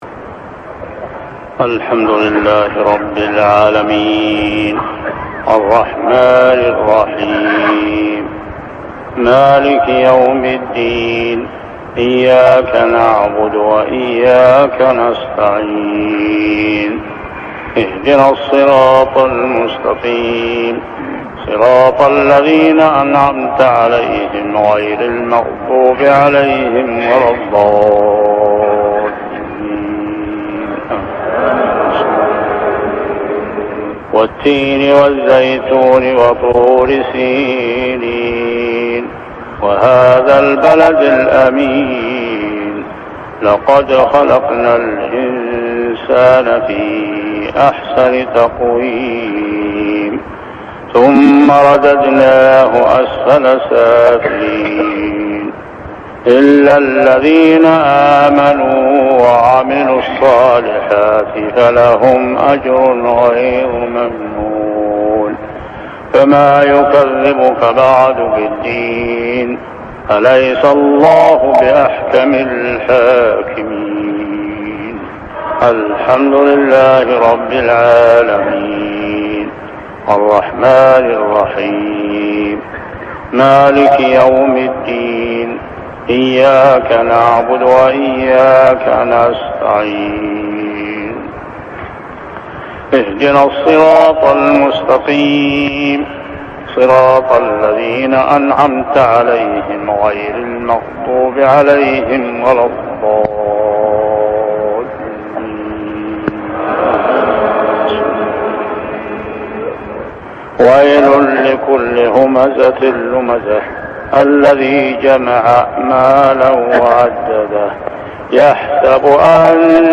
صلاة المغرب عام 1399هـ سورتي التين و الهمزة كاملة | maghrib prayer Surah At-Tin and Al-Hamza > 1399 🕋 > الفروض - تلاوات الحرمين